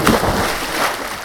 Collision-splash-medium.wav